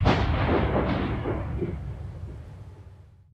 lightning_1.ogg